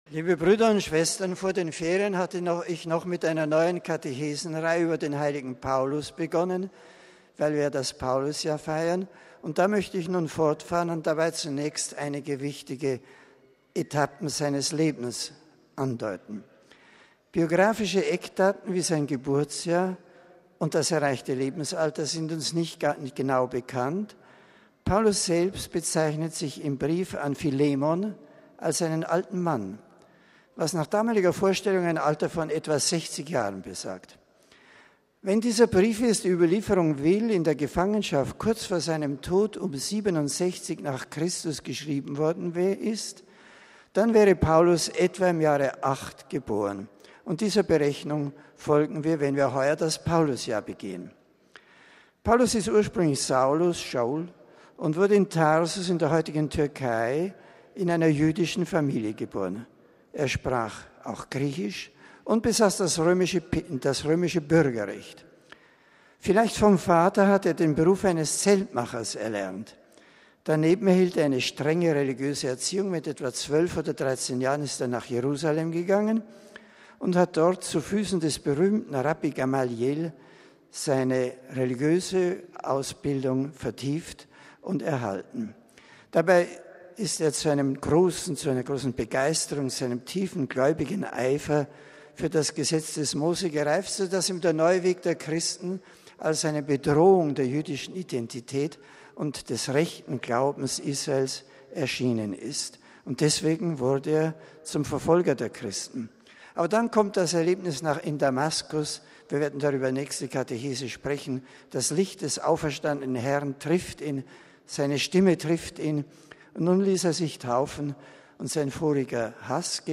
Weil sich nach Vatikanangaben rund 8.000 Personen zu dem Treffen mit dem Papst angemeldet hatten, fand der Termin wieder in der vatikanischen Audienzhalle statt.
Besonders stürmisch begrüßten 600 Ministranten aus der Steiermark den Papst, die mit Tücherwinken auf sich aufmerksam machten.